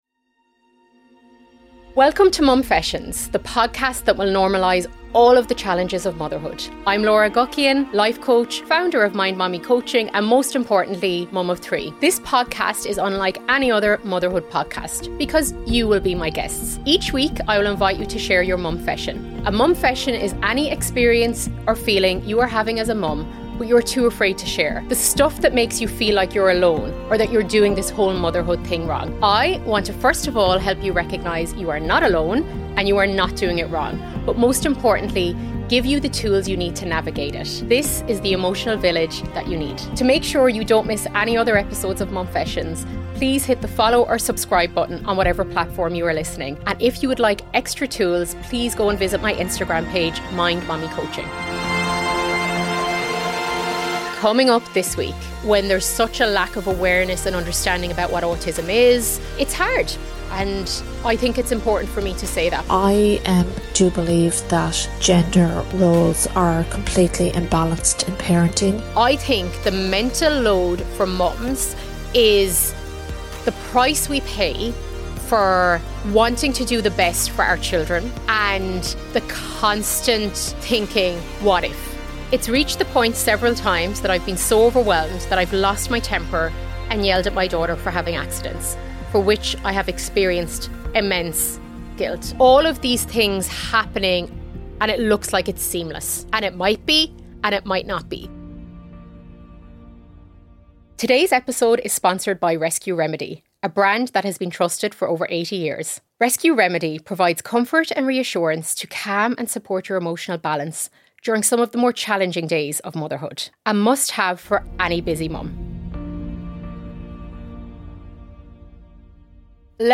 I am also joined by 2 x amazing Moms who share their Momfessions.